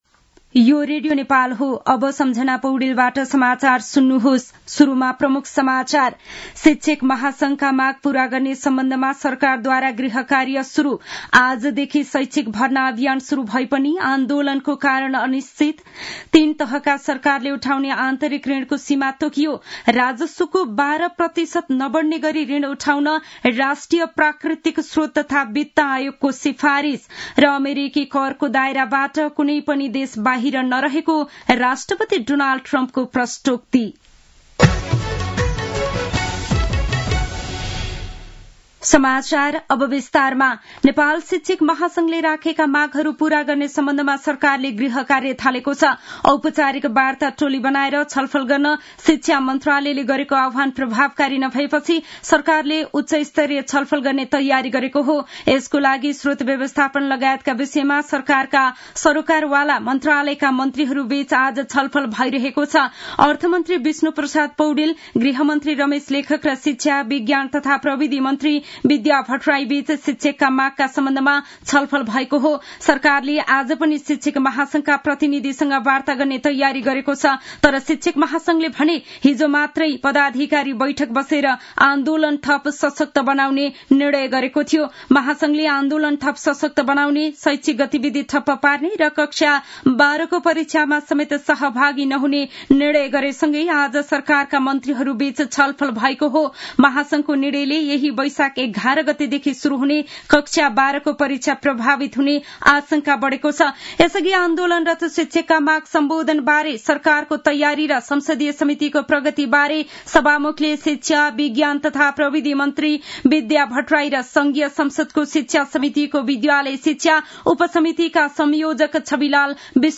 दिउँसो ३ बजेको नेपाली समाचार : २ वैशाख , २०८२
3-pm-news-1-3.mp3